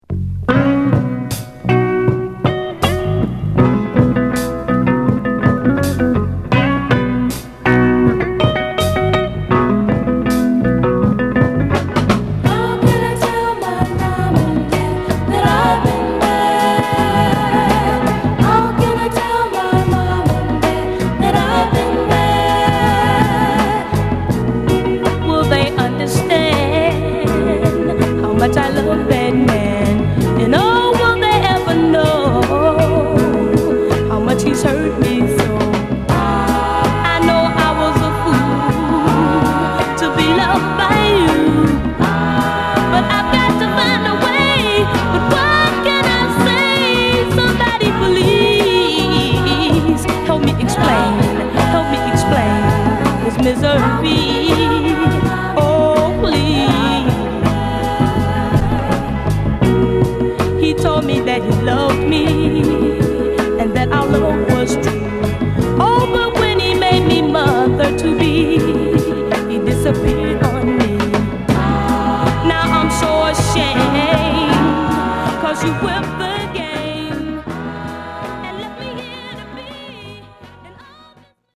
ゆったりしつつもボトムのしっかりした演奏にラブリーなヴォーカル/コーラスをのせる極上のメロウソウル！